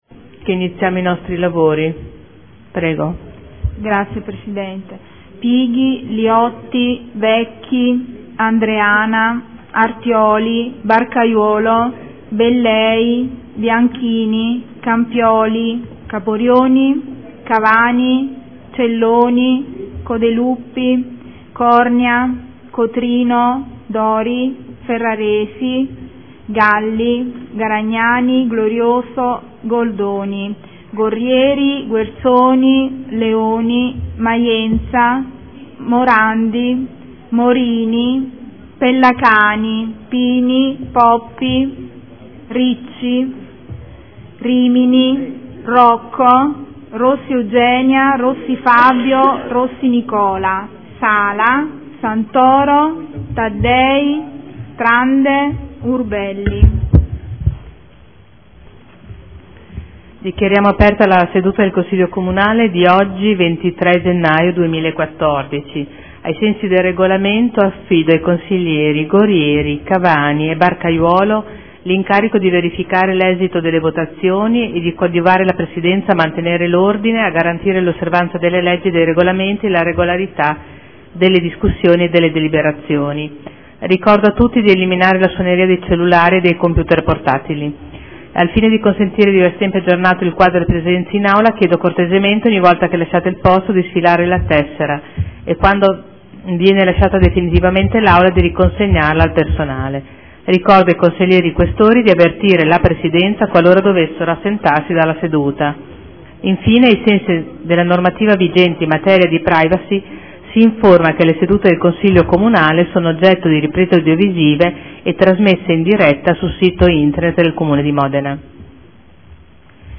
Seduta del 23/01/2014 Appello.
Segretario